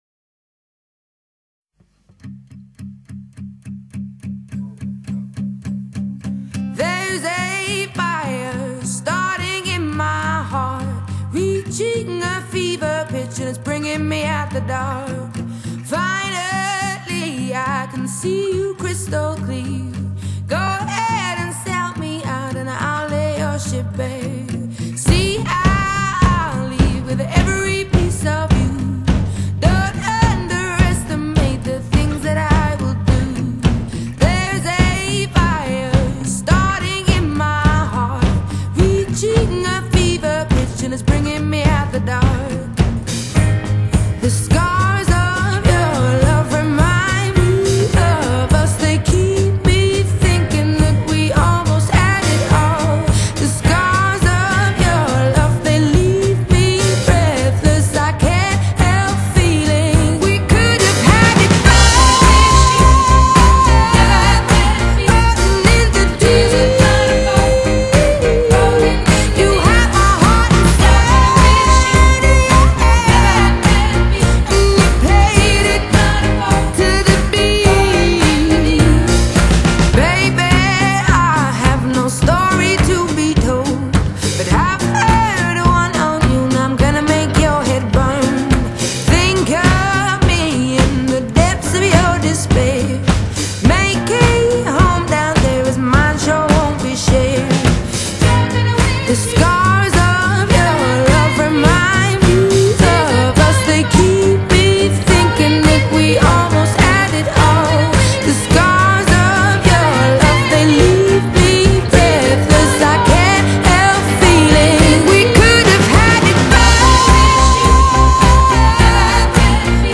Genre: Blues / Funk / Soul / Jazz